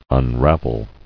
[un·rav·el]